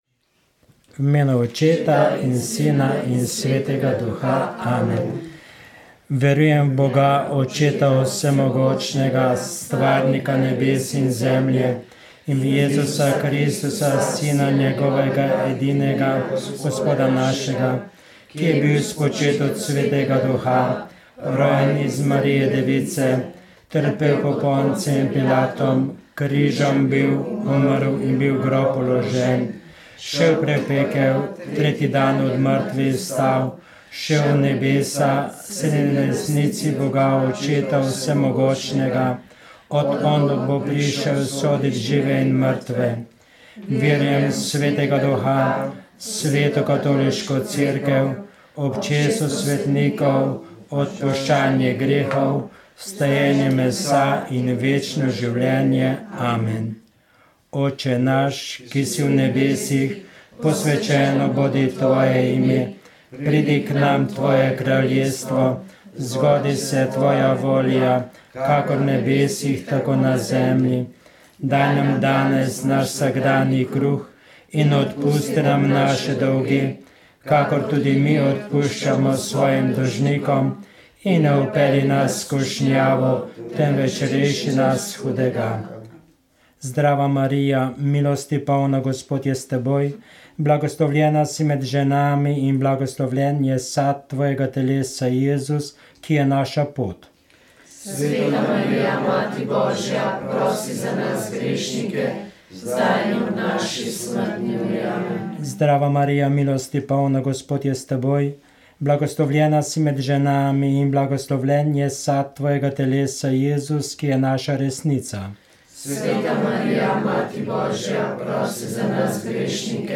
Rožni venec
Molili so člani skupnosti Pelikan in Karitas.